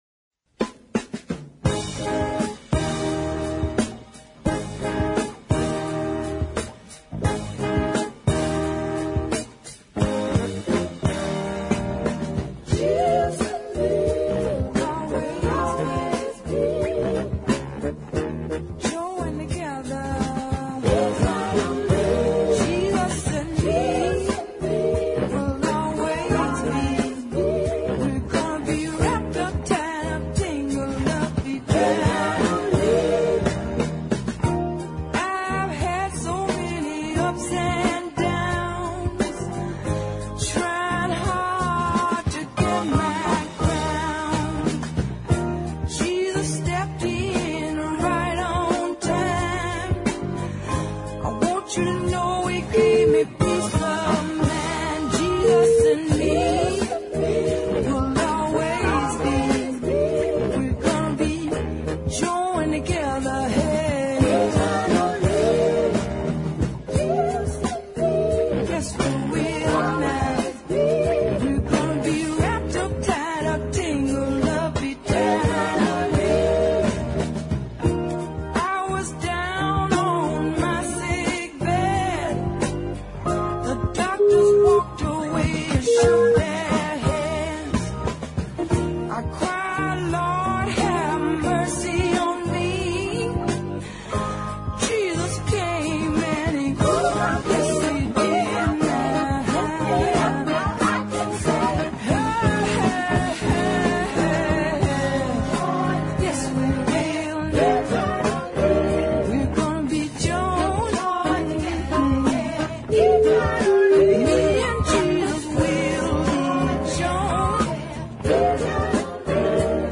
This is a nice laid-back, midtempo tune.